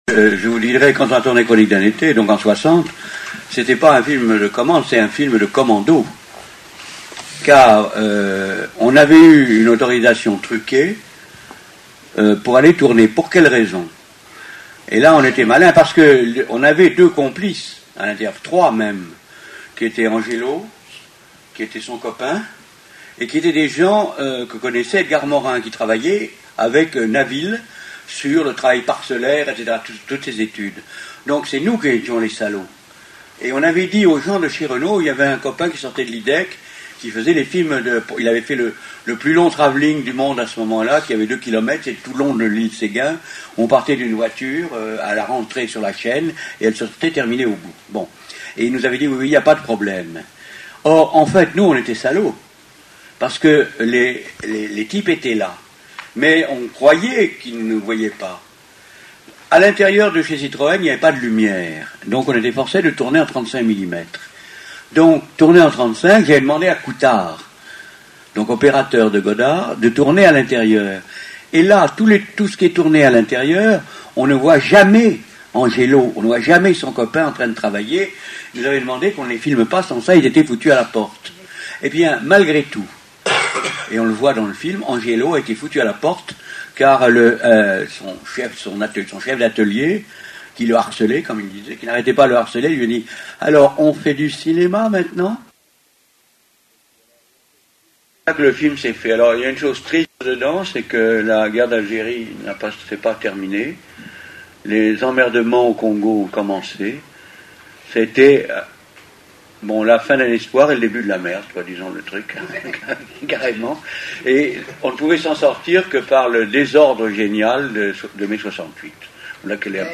Sur cette page vous pouvez entendre Jean ROUCH, Marceline LORIDAN et Michel BRAULT qui évoquent leurs souvenirs du tournage du film d’Edgar MORIN et de Jean ROUCH, Chronique d’un été.